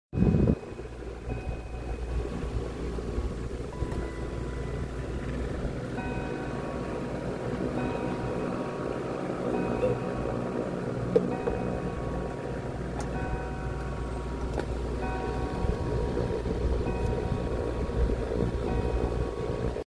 HM Thomas Tompion Strikes 8AM sound effects free download